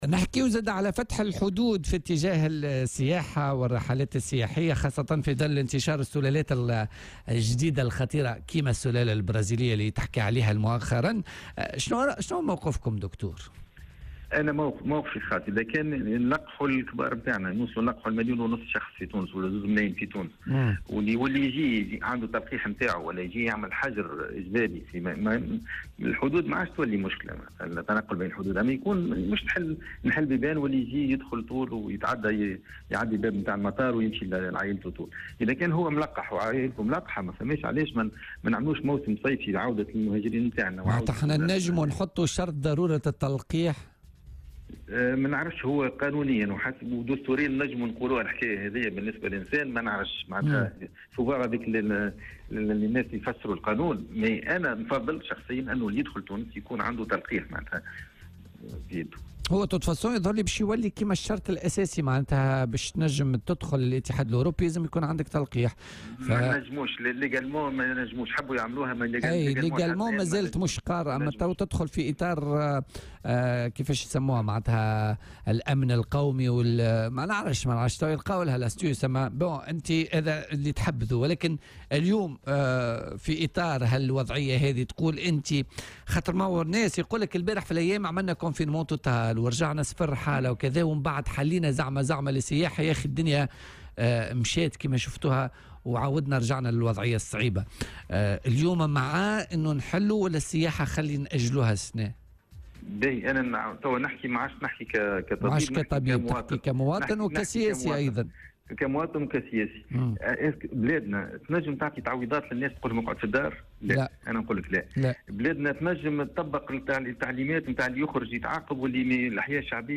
مداخلة له اليوم على "الجوهرة أف أم"